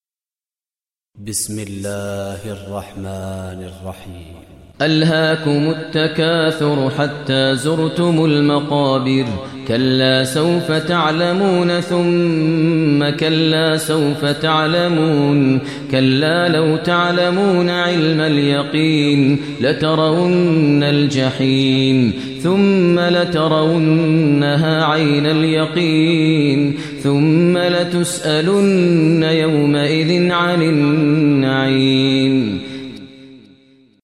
Surah Takasur Recitation by Maher al Mueaqly
Surah Takasur, listen online mp3 tilawat / recitation in Arabic recited by Imam e Kaaba Sheikh Maher al Mueaqly.